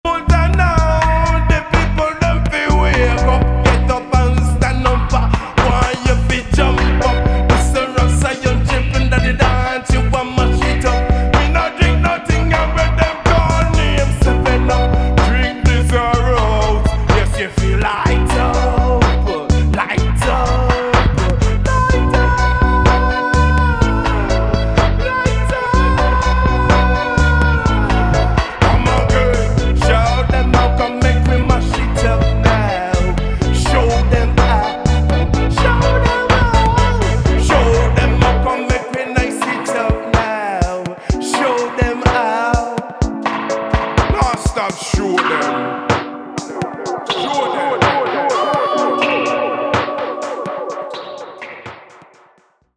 伝統的な要素と先鋭的な要素がブレンドされたレゲエ作品に仕上がっています。